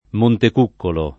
montek2kkolo] top. (E.-R.)